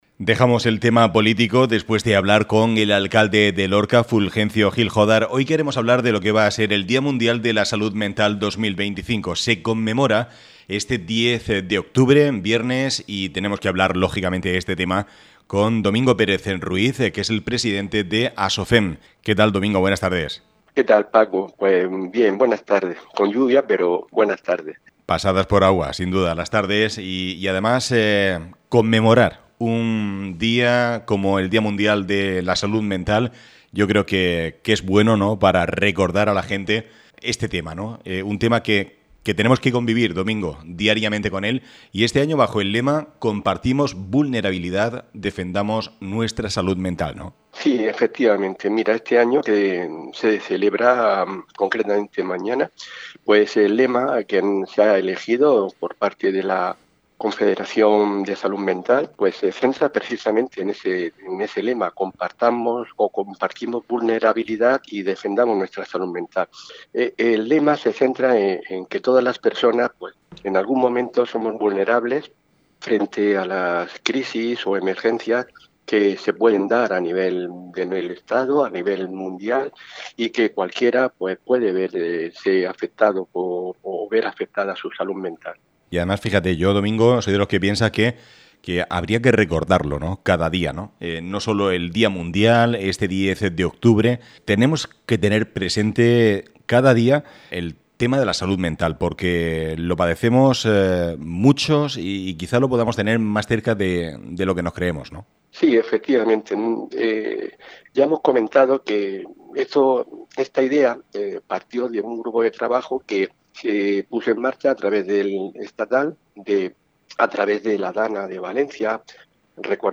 Actualidad.